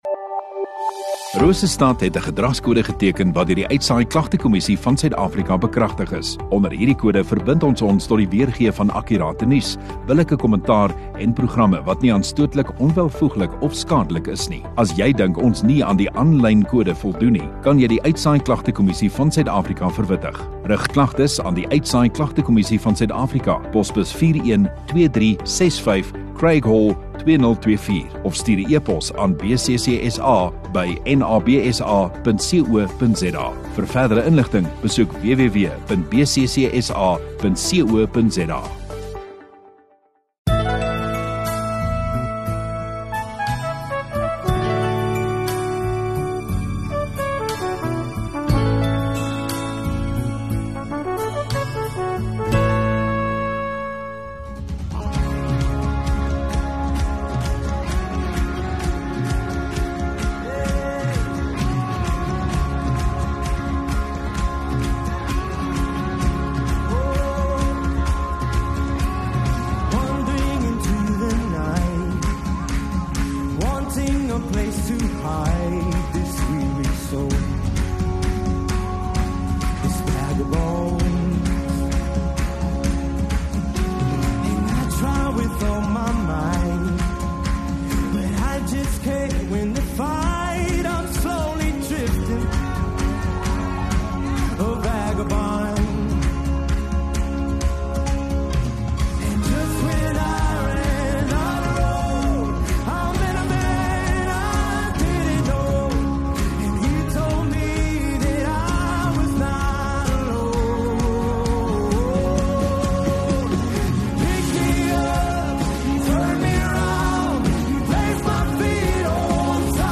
20 Oct Sondagaand Erediens